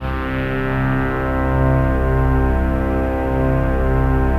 XXL 800 Pads